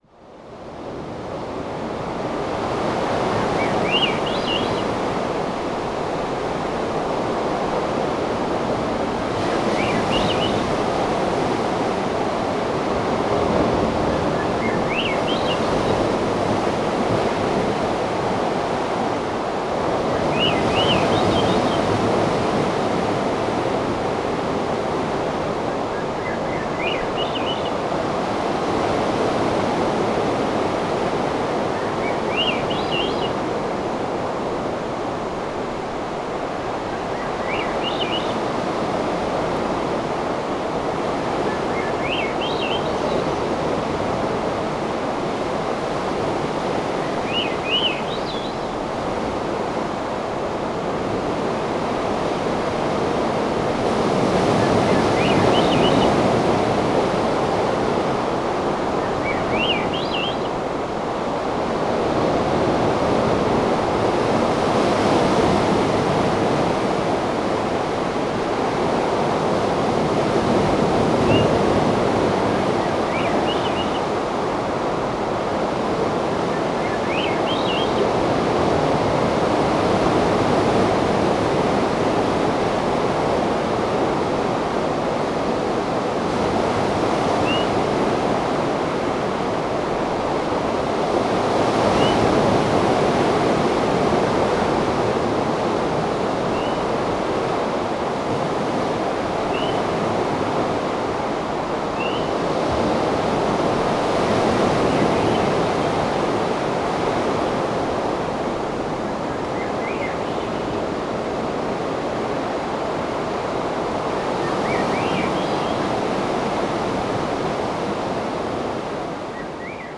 Recordings from Juan de Fuca Provincial Park
57. Swainson’s Thrush solo (song and calls) accompanied by Botanical Beach waves
57_Swainsons_thrush_Botanical_waves.wav